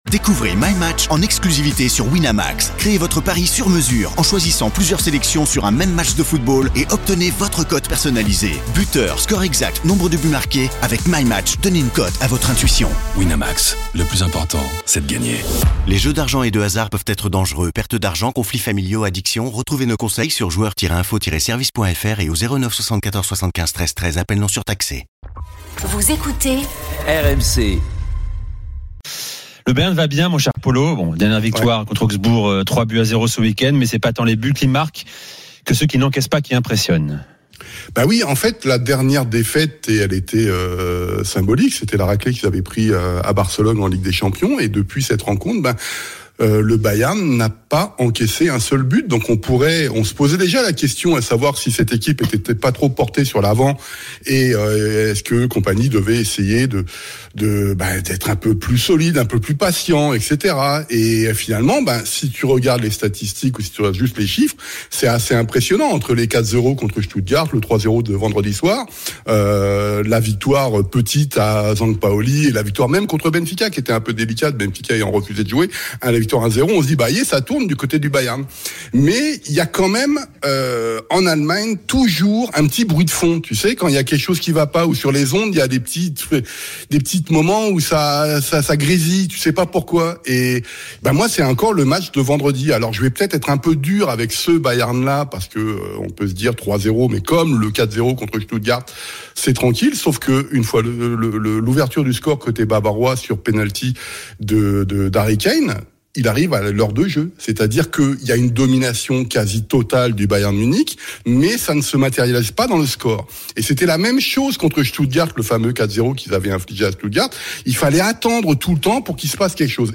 Au programme, des débats passionnés entre experts et auditeurs RMC, ainsi que de nombreux invités.